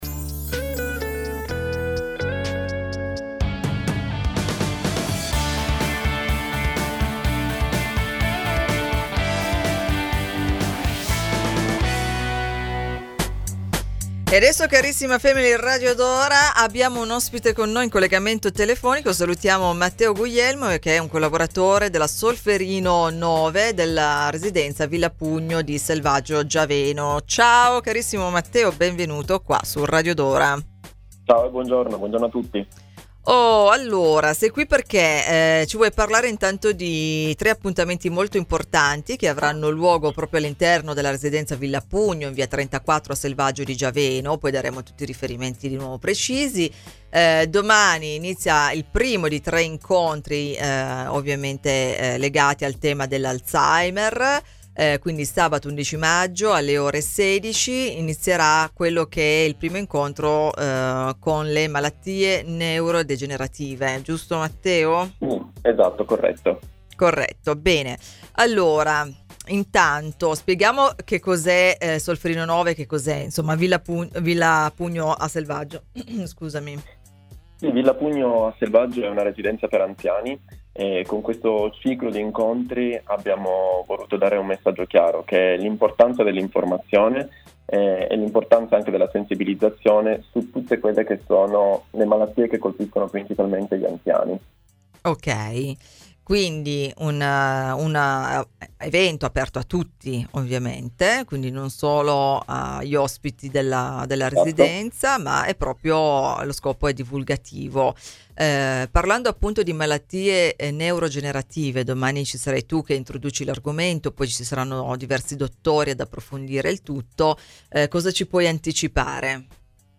su Radio Dora